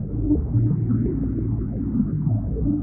Index of /musicradar/rhythmic-inspiration-samples/85bpm